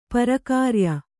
♪ parakārya